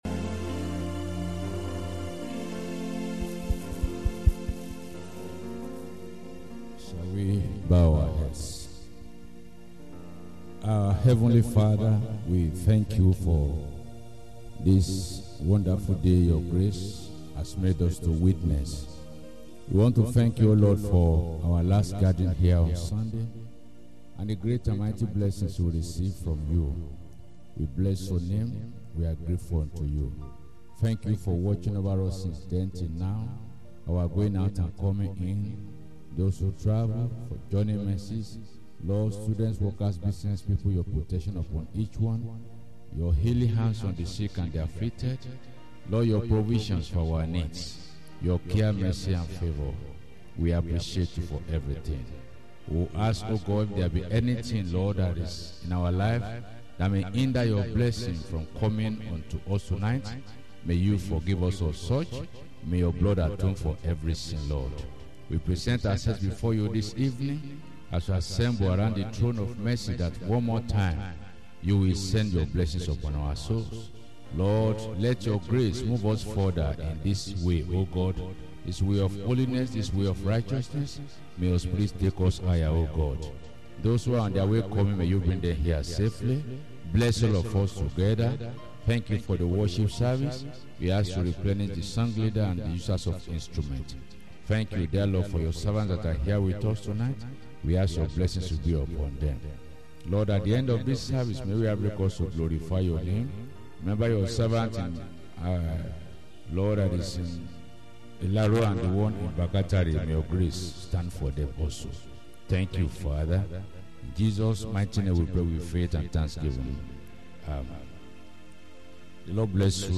WED.. PRAYER MEETING